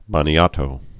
(bōnētō)